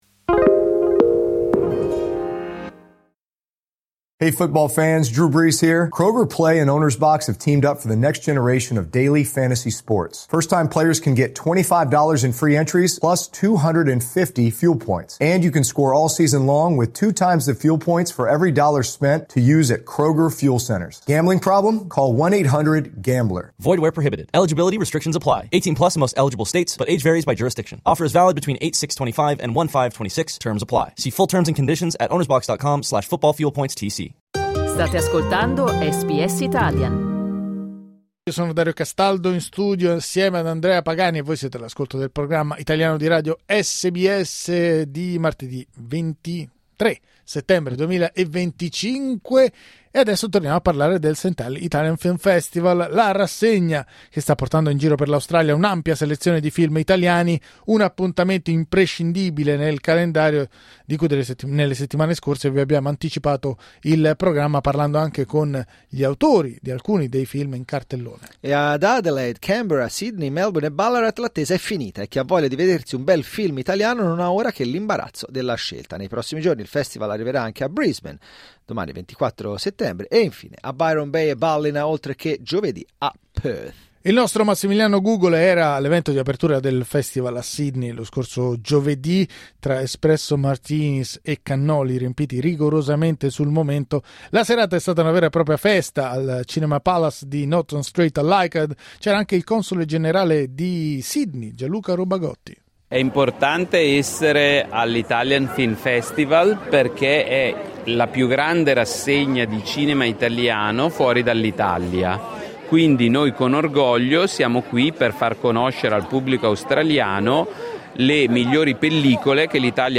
Ecco il nostro racconto della serata di apertura a Sydney del ST ALi Italian Film Festival, la rassegna che sta portando in giro per l'Australia in queste settimane un'ampia selezione di film italiani.